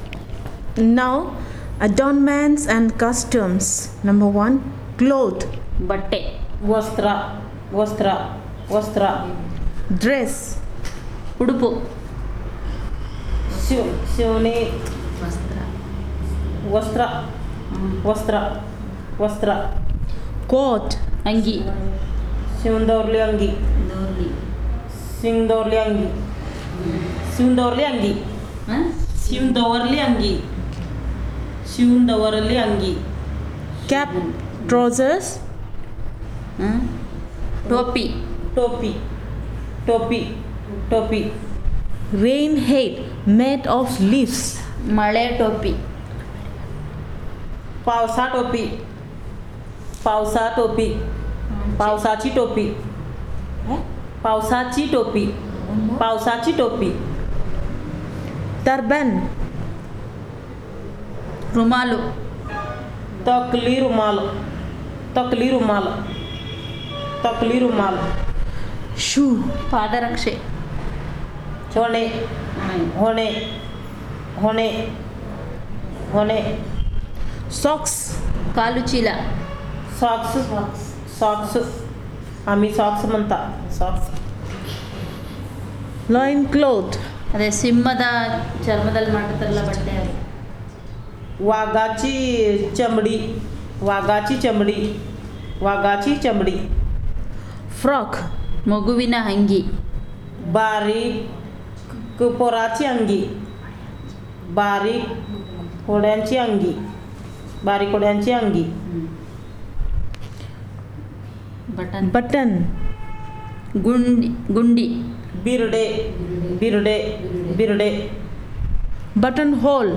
Elicitation of words about adornments and costumes